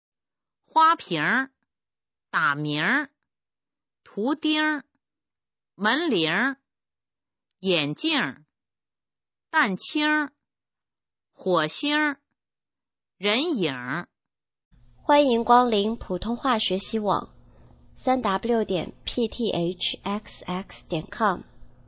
普通话水平测试用儿化词语表示范读音第14部分